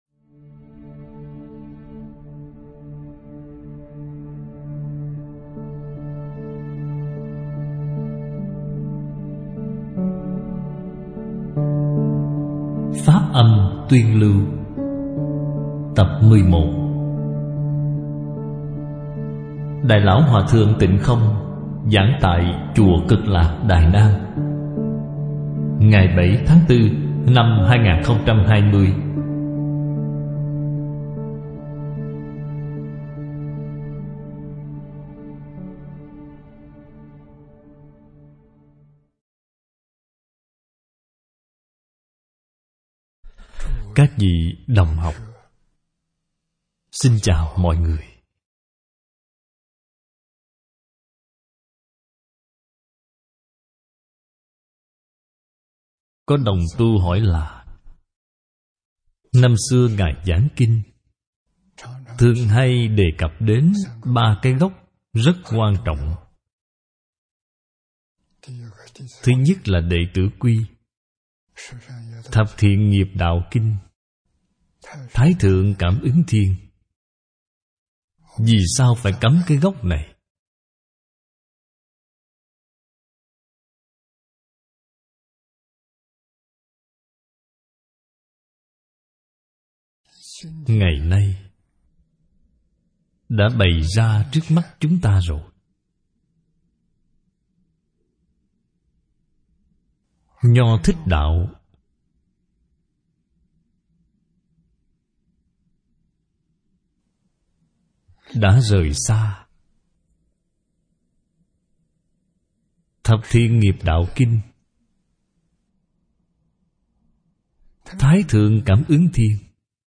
Làm Thế Nào Để Hóa Giải Kiếp Nạn | Tập 7/7 Phóng Viên Phỏng Vấn Pháp Sư Tịnh Không